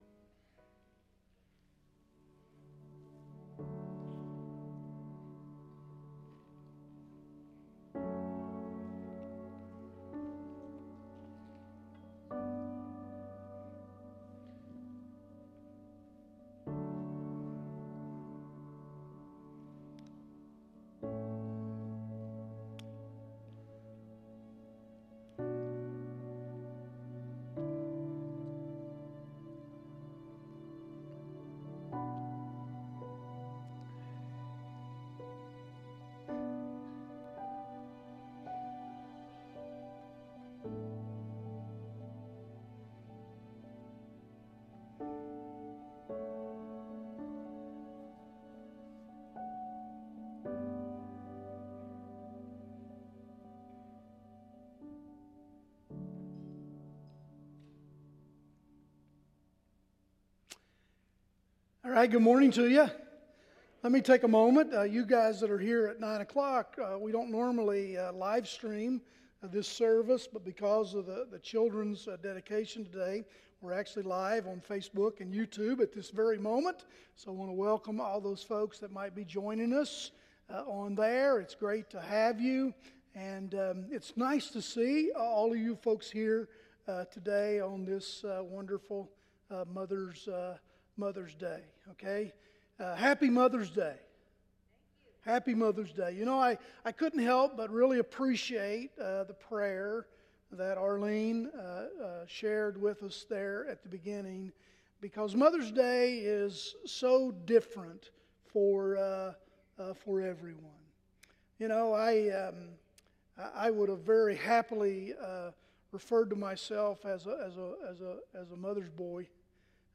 Sermon Description